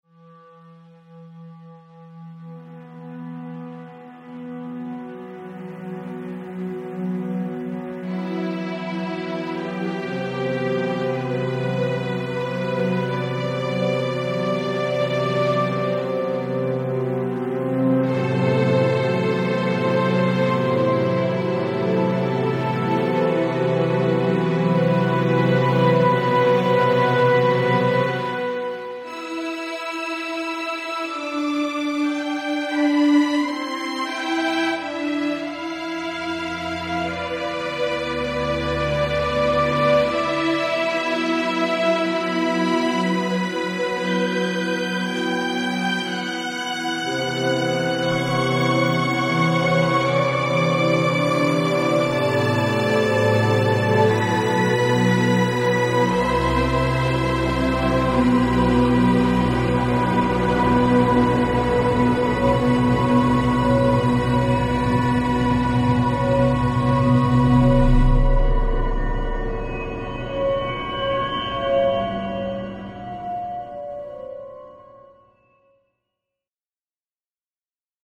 Melodram in vier Sätzen
(für Stimme & artificial orchestra)
Style: from jazz to orchestral filmmusic